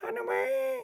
SFX / Characters / Voices / PigChef